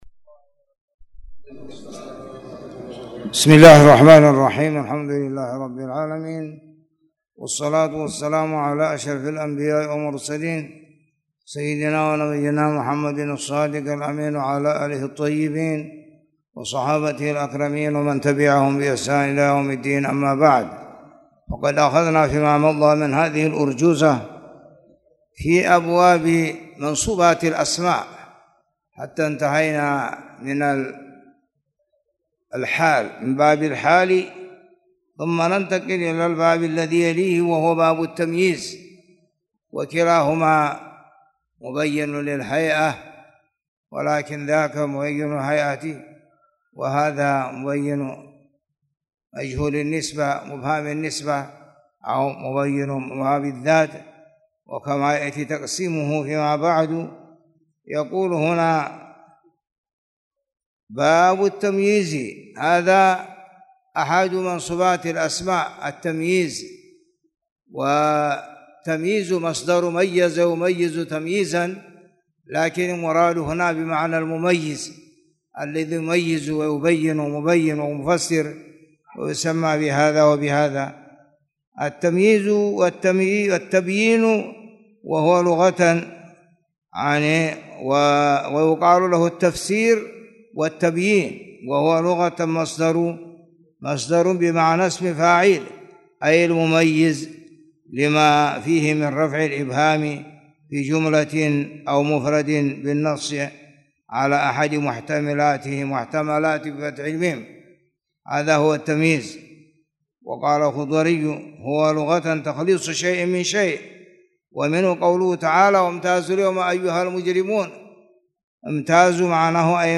تاريخ النشر ٩ ربيع الأول ١٤٣٨ هـ المكان: المسجد الحرام الشيخ